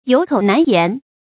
有口难言 yǒu kǒu nán yán
有口难言发音
成语正音难，不能读作“nàn”。